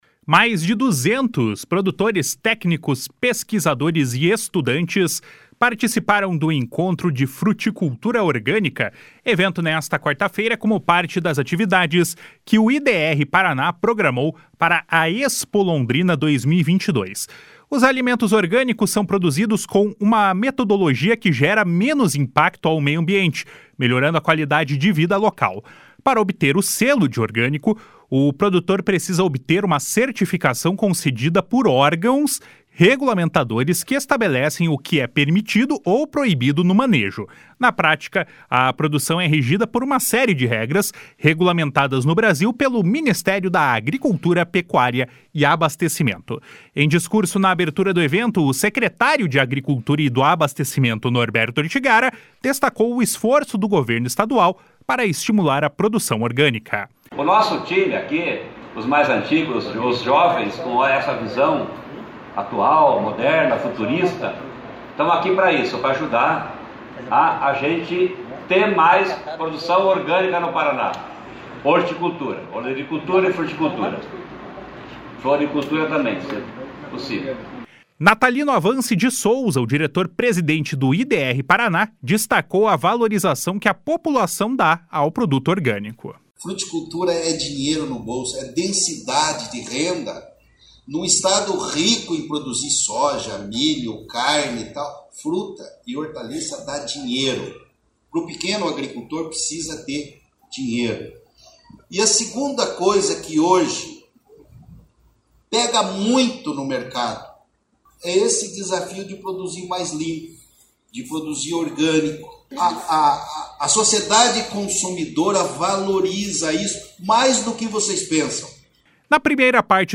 Em discurso na abertura do evento, o secretário de Agricultura e do Abastecimento, Norberto Ortigara, destacou o esforço do governo estadual para estimular a produção orgânica. // SONORA NORBERTO ORTIGARA //